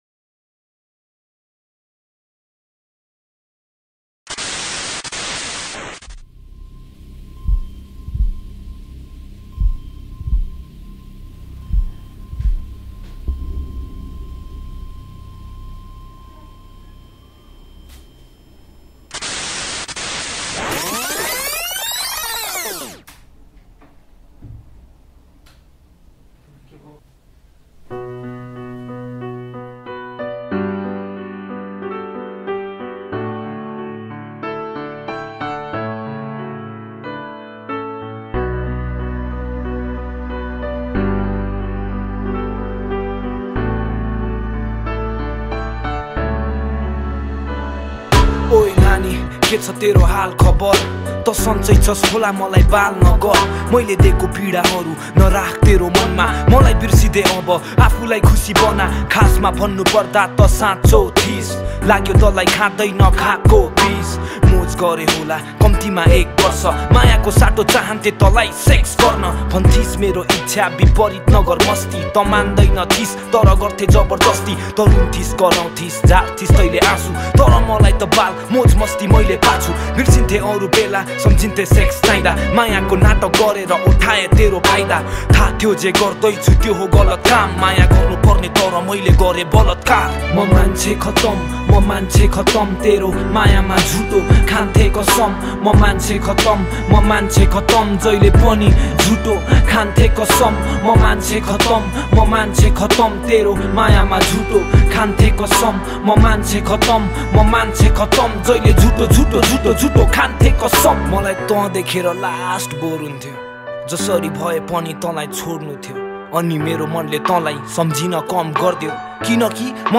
# Nepali Mp3 Rap Song